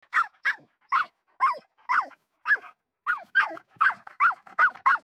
Dog Fighting Sound Button - Free Download & Play